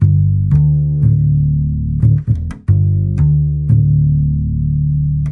chillout " 爵士贝斯A 5
描述：爵士乐，音乐，爵士乐
声道立体声